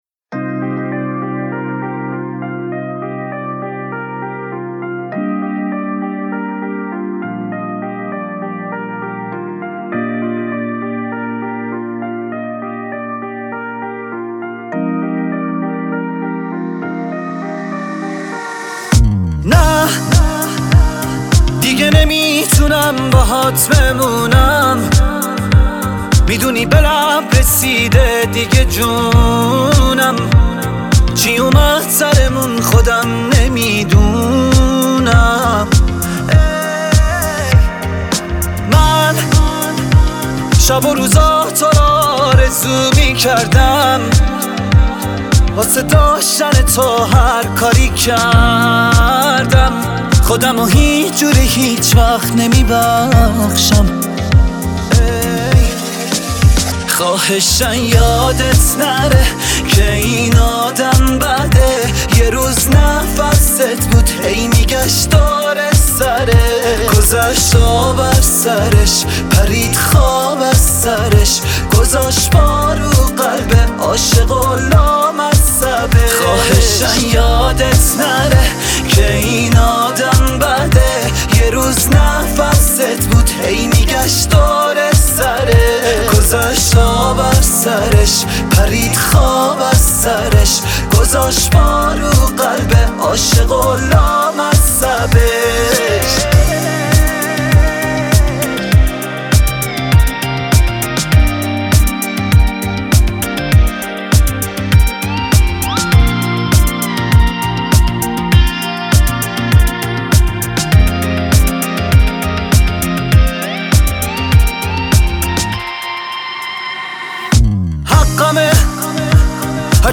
با صدای احساسی